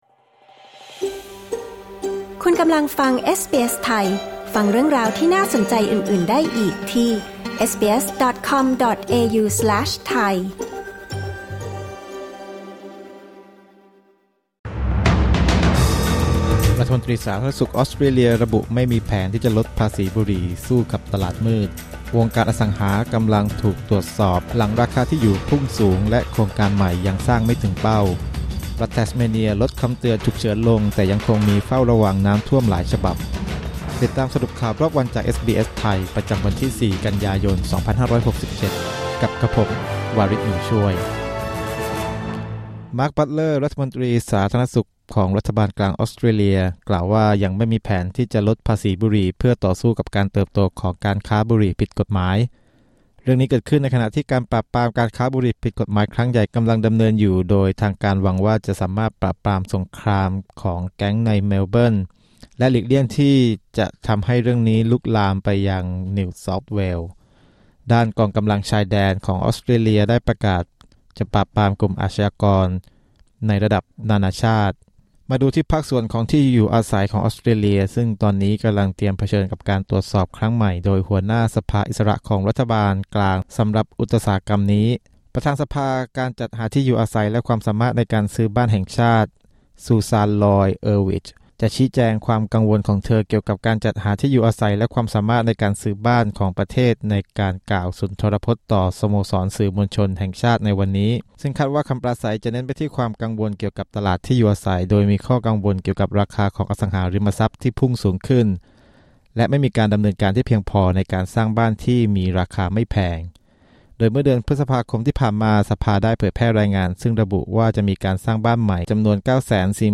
คลิก ▶ ด้านบนเพื่อฟังรายงานข่าว